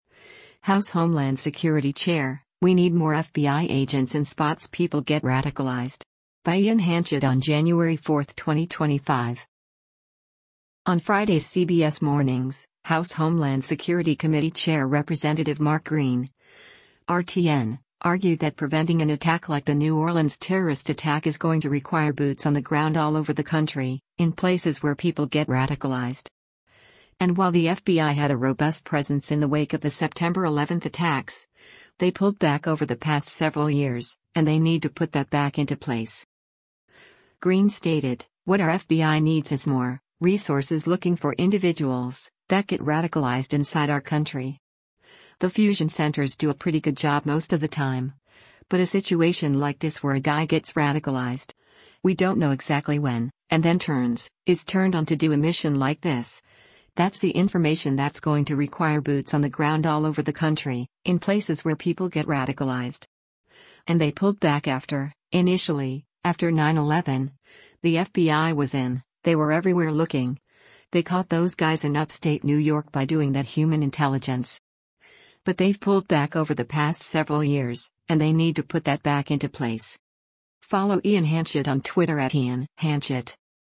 On Friday’s “CBS Mornings,” House Homeland Security Committee Chair Rep. Mark Green (R-TN) argued that preventing an attack like the New Orleans terrorist attack is “going to require boots on the ground all over the country, in places where people get radicalized.”